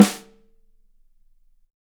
Drums